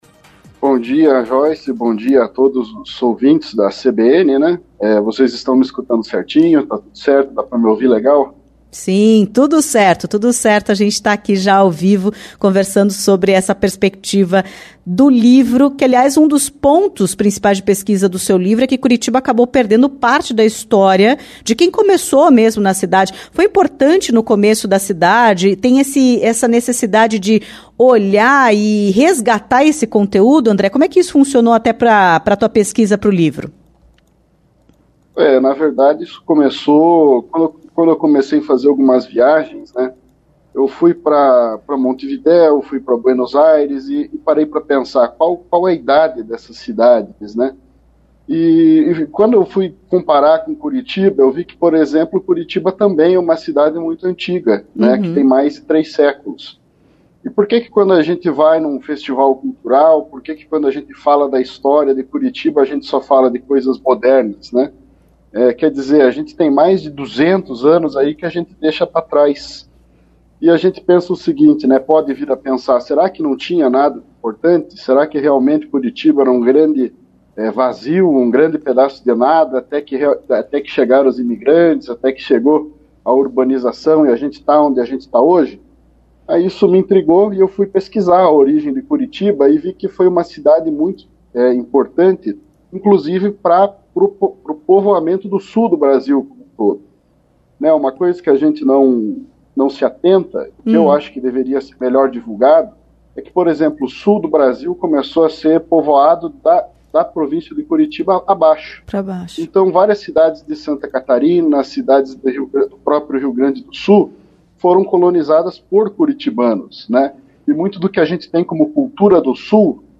entrevista-15-11.mp3